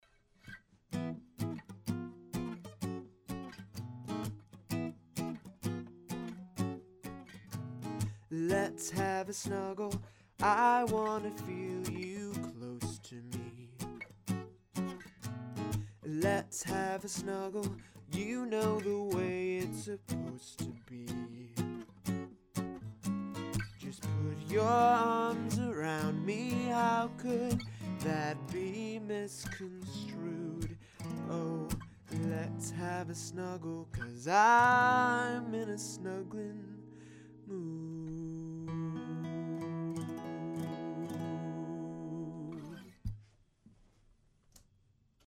This is a tiny little ode to snuggling in the jazzy style I've been exploring.
Great vocal take.
4. Great chords and melody